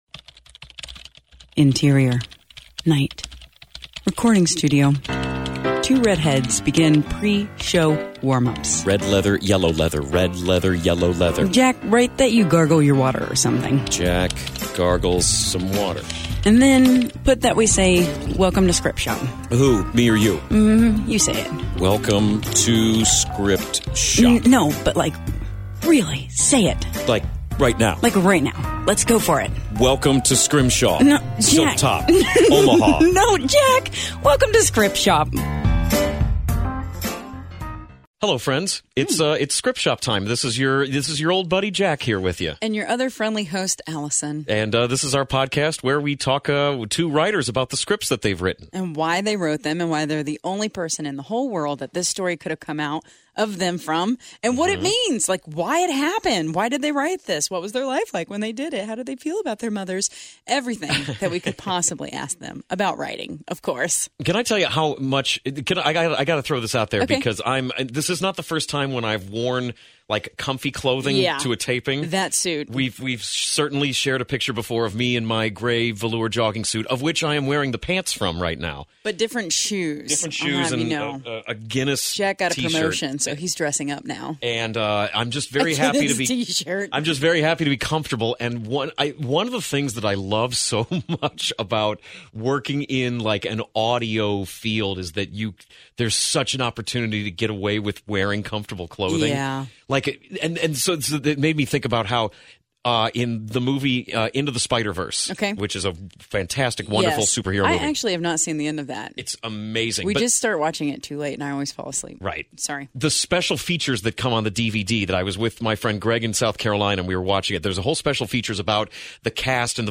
Script Shop Podcast Interview